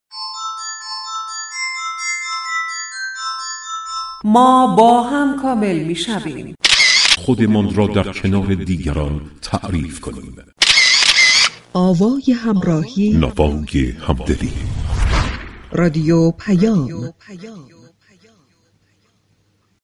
به گزارش رادیو پیام از خبرآنلاین، آیت‌الله محمد امامی كاشانی ظهر امروز (جمعه) در سخنان خود در نماز جمعه این هفته تهران با اشاره به راهپیمایی 22 بهمن اظهار كرد: 22 بهمن نوری بود و به كشورهای دیگر نیز رفت.